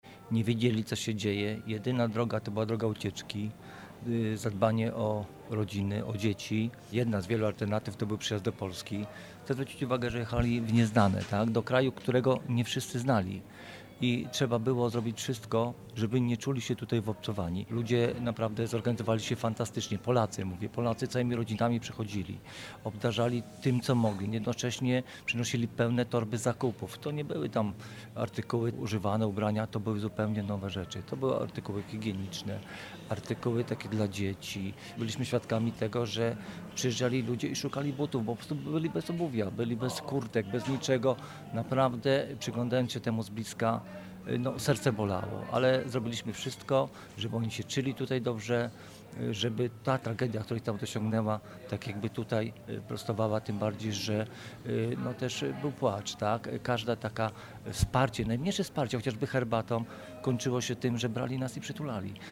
Nadajemy program prosto z Dworca Głównego PKP we Wrocławiu, z kawiarni Stacja Dialog.